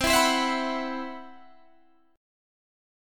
Listen to CM#11 strummed